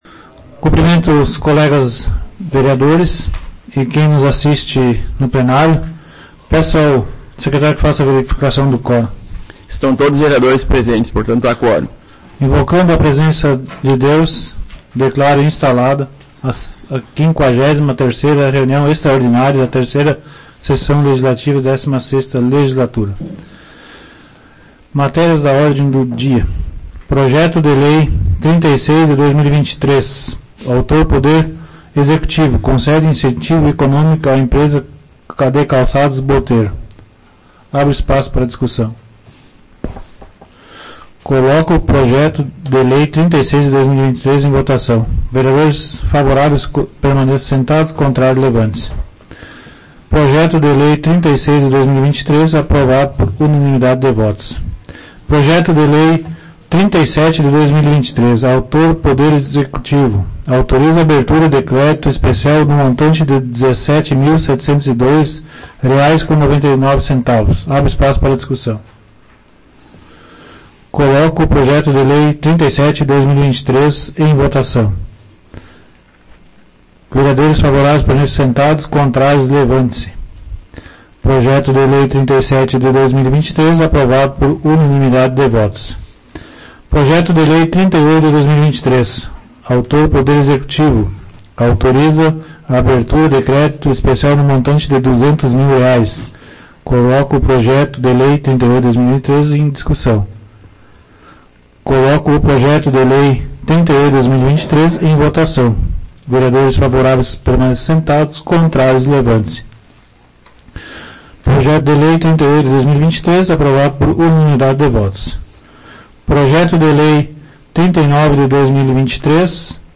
Áudio da 53ª Sessão Plenária Extraordinária da 16ª Legislatura, de 12 de junho de 2023